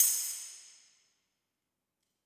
JJPercussion (24).wav